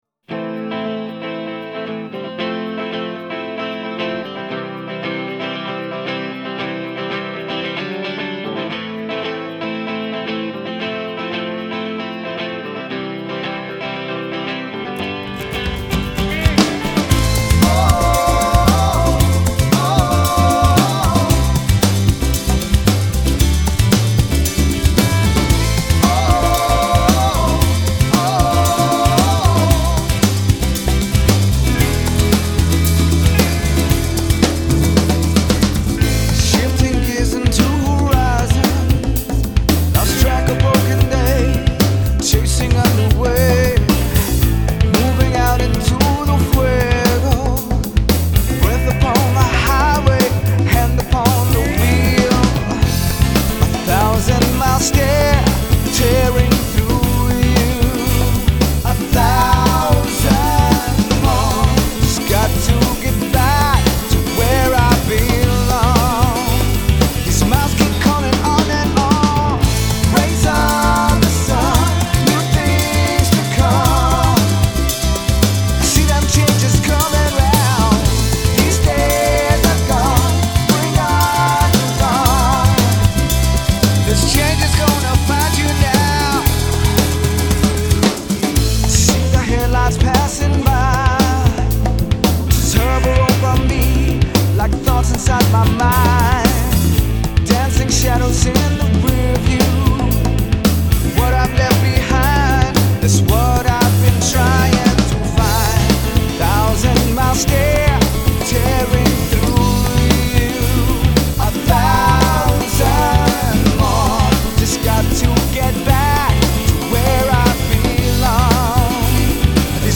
guitars, mellotron
lead vocals
drums & percussion
keyboards